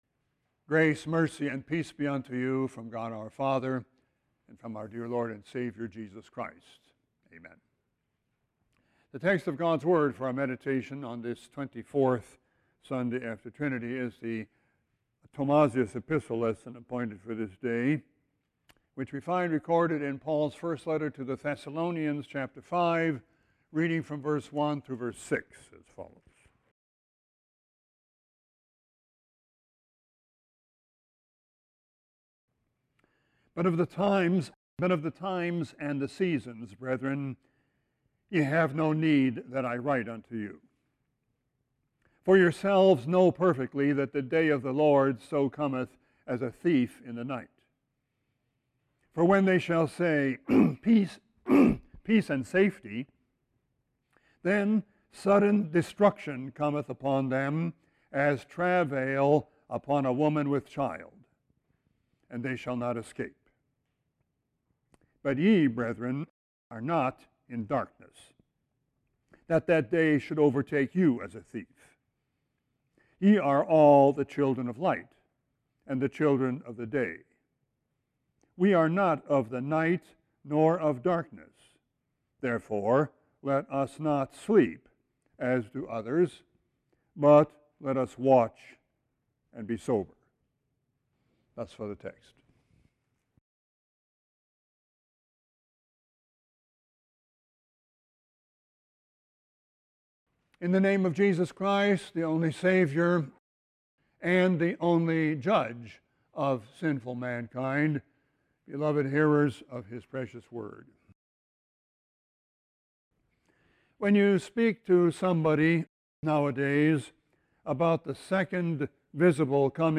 Text: 1 Thessalonians 5:1-6 This text will be replaced by the JW Player Right click & select 'Save link as...' to download entire Sermon video Right click & select 'Save link as...' to download entire Sermon audio